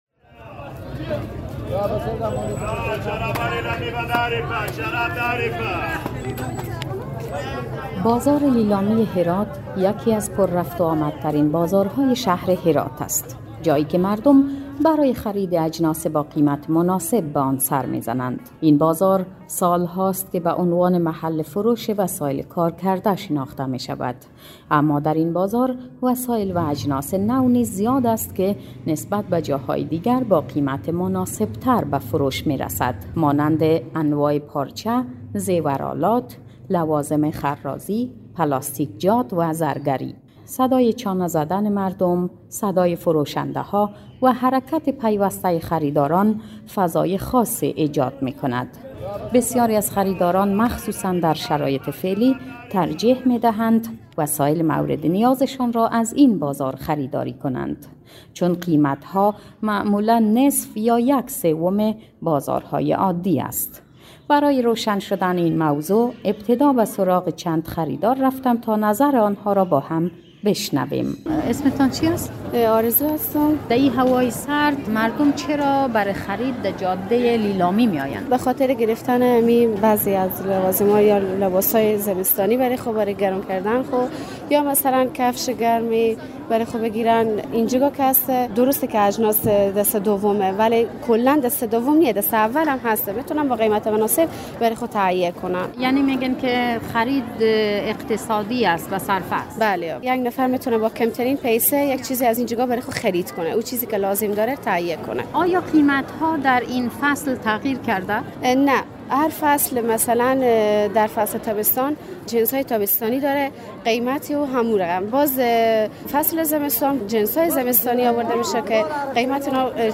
در این بازار صدای چانه‌زدن مردم، صدای فروشنده‌ها و حرکت پیوسته‌ی خریداران فضای خاصی ایجاد می‌کند.